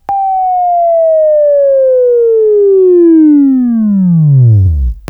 Buzz